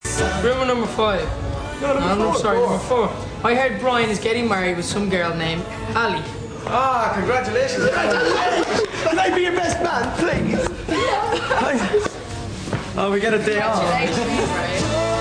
Parts of the interview (the blue ones) are also recorded in MP3 format!!!